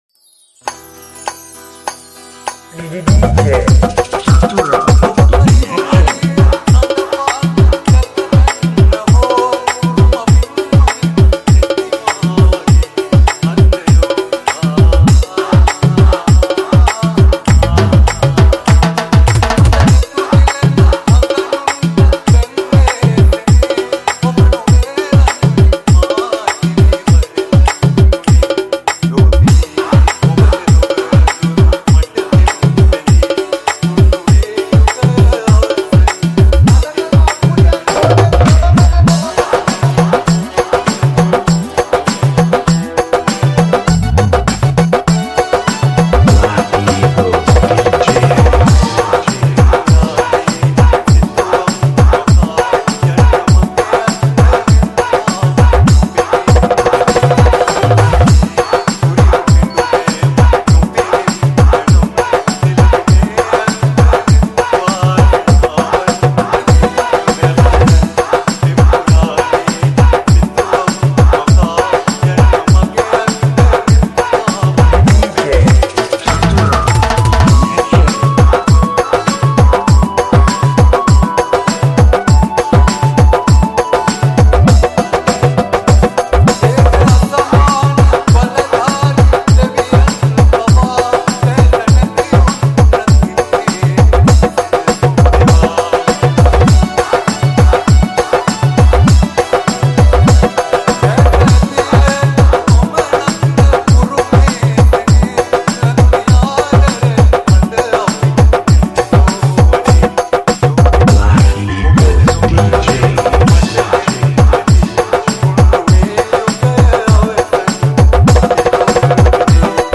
Dolak Style DJ Song